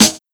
• Treble-Heavy Snare Drum Sample G Key 03.wav
Royality free snare drum sample tuned to the G note. Loudest frequency: 4138Hz
treble-heavy-snare-drum-sample-g-key-03-fd4.wav